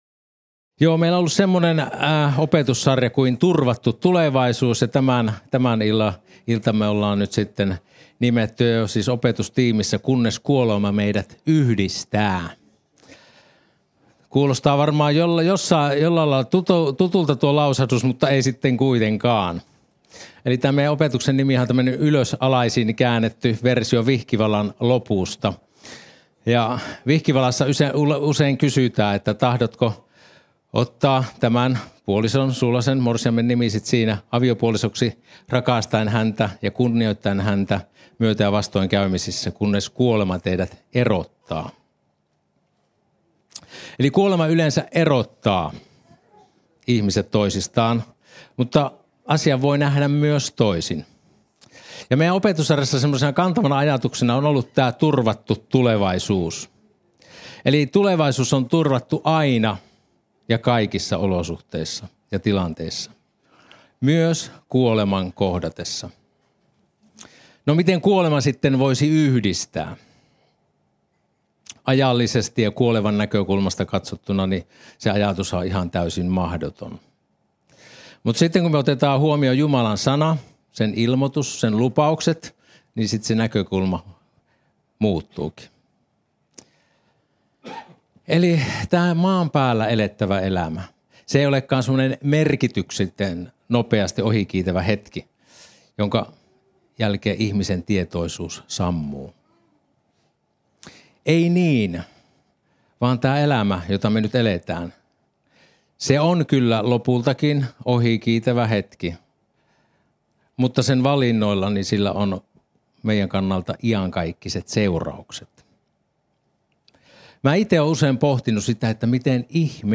Opetus ja messikysymykset